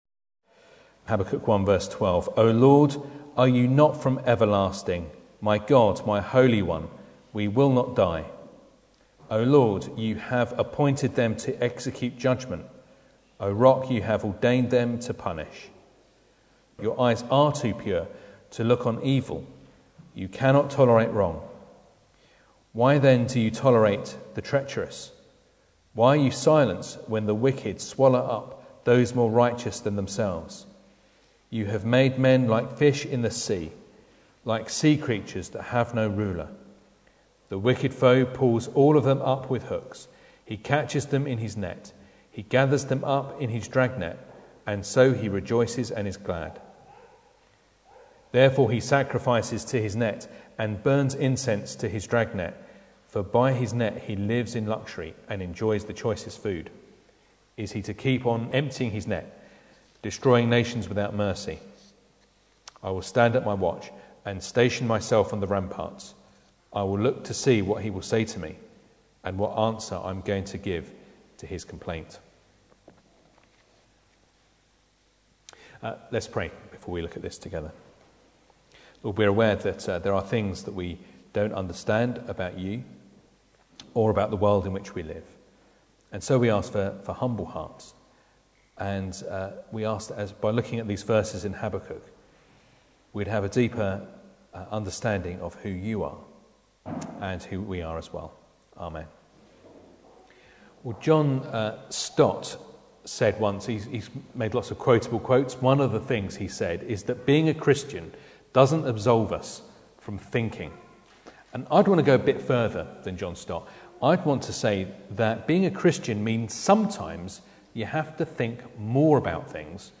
Habakkuk 1:12-2:1 Service Type: Sunday Morning Bible Text